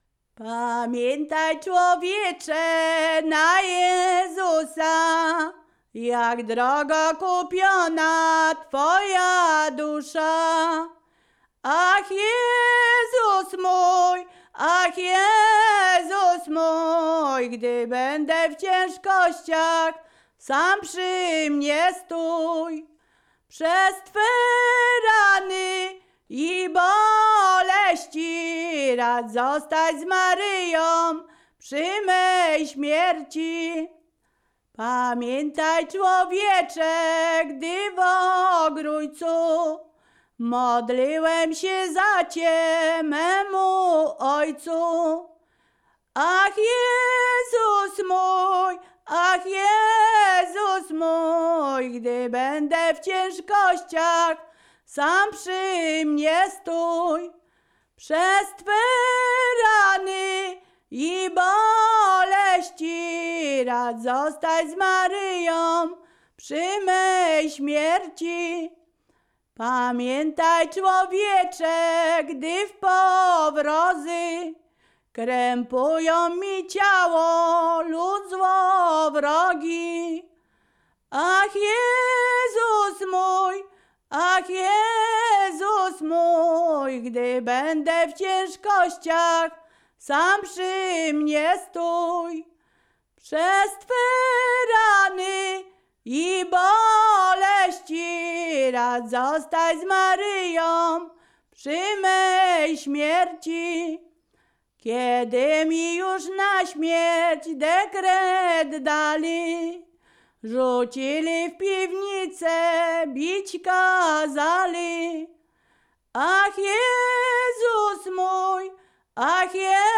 Ziemia Radomska
Wielkopostna
pogrzebowe nabożne katolickie do grobu wielkopostne